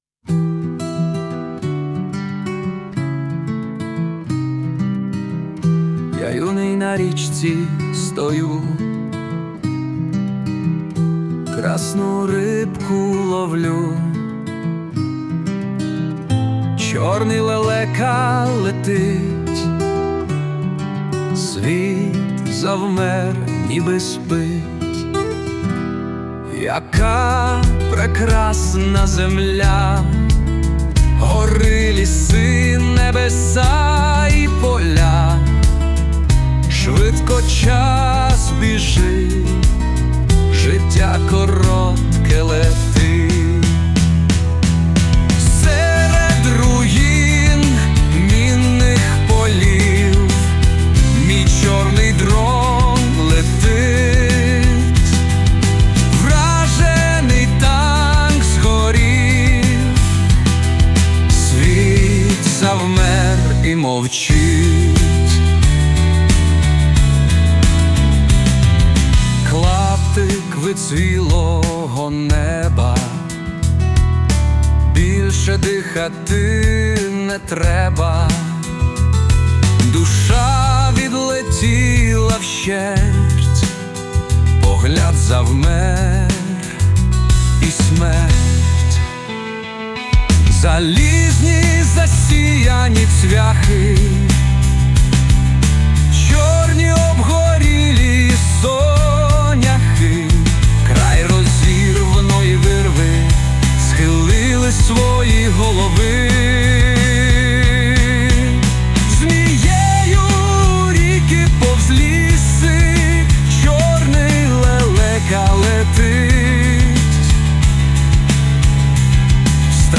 ТИП: Пісня
СТИЛЬОВІ ЖАНРИ: Ліричний